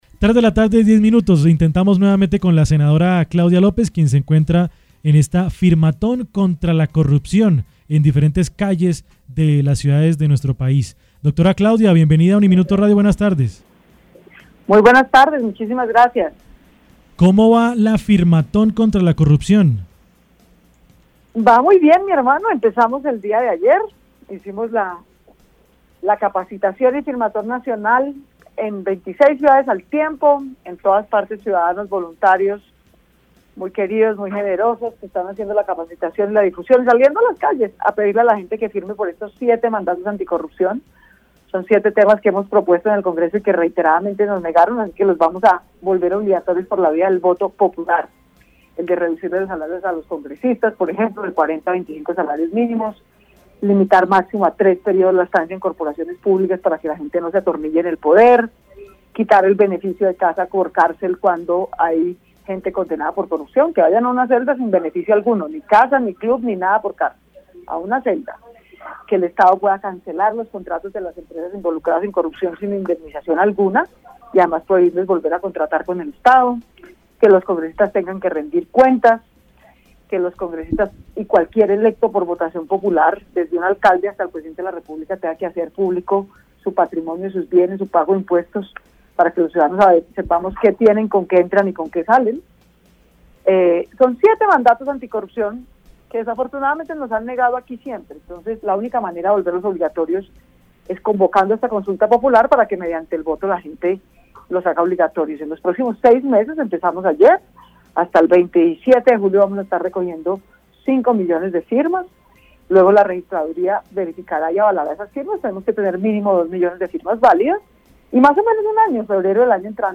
El presidente Juan Manuel Santos, estuvo en exclusiva para UNIMINUTO Radio en el marco de la Feria Internacional del Libro de Bogotá realizada en abril. Santos sostuvo que la crítica es muy importante para crecer como persona e hizo una invitación a todos los oyentes a creer cada día en una paz duradera.